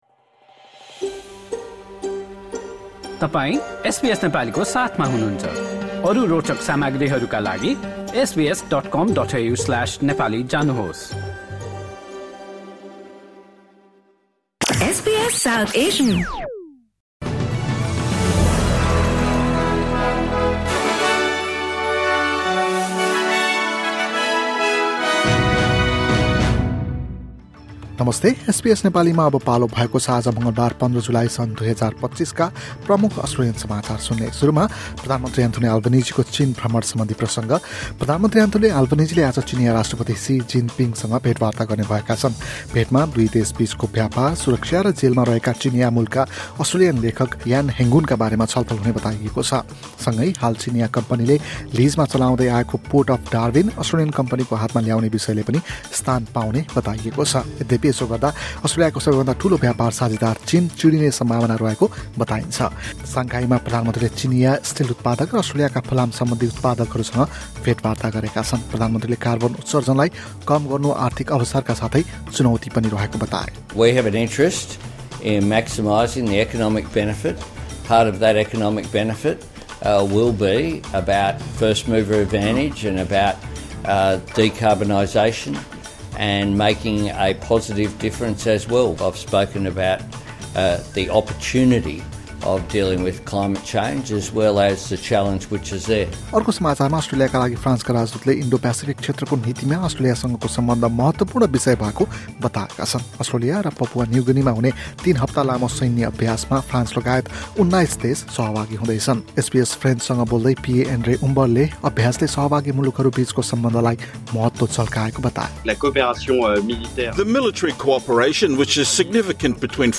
SBS Nepali Australian News Headlines: Tuesday, 15 July 2025